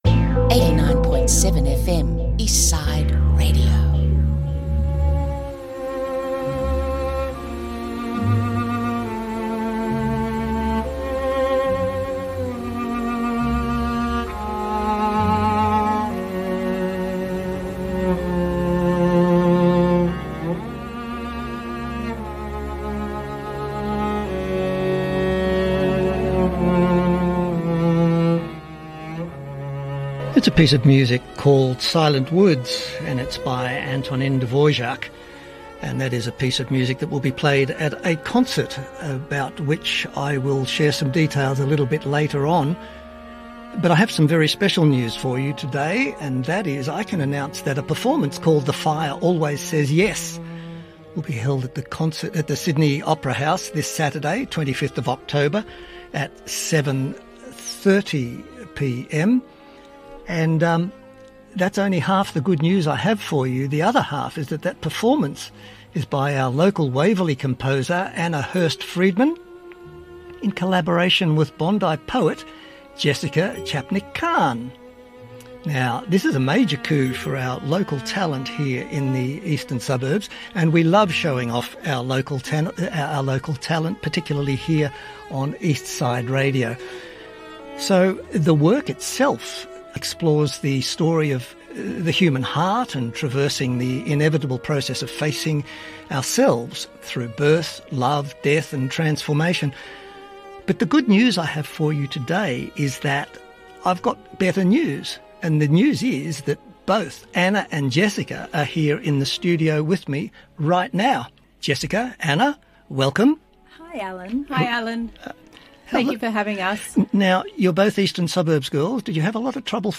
Eastside Radio Interview discussing The Fire Always Says Yes